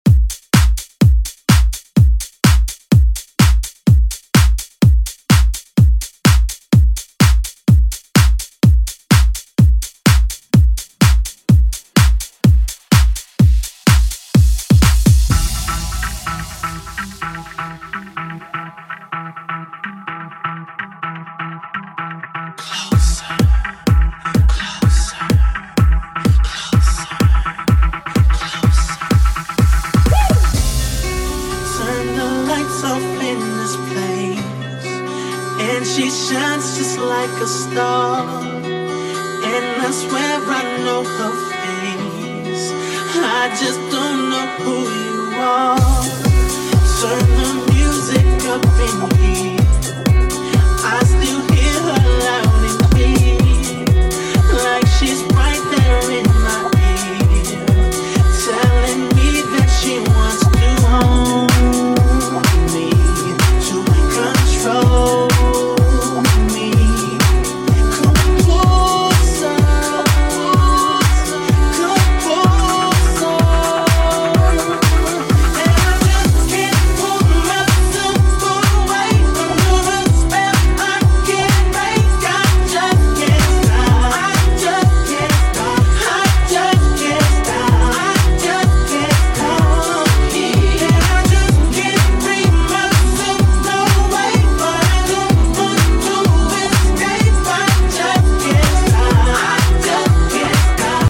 Genres: DANCE , EDM , RE-DRUM